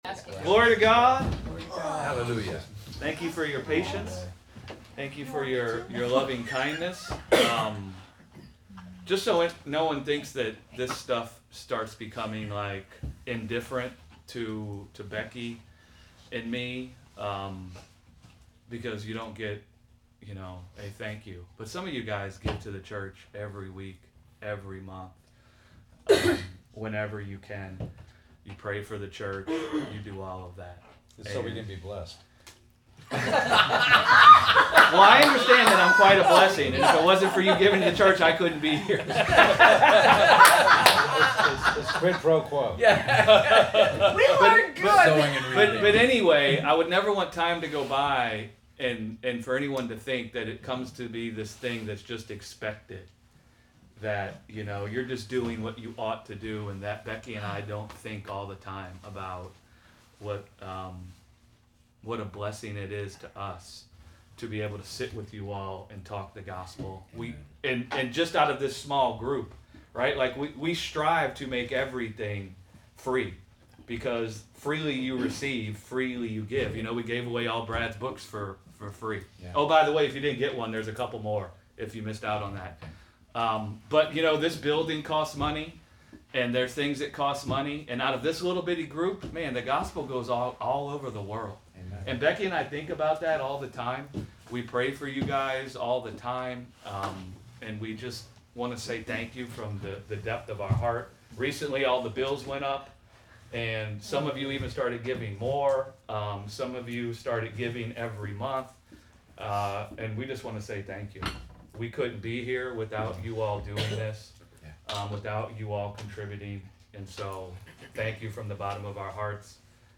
Sunday Bible Study | Is the Chastening of the Lord a Form of Punishment or a Restorative Measure - Gospel Revolution Church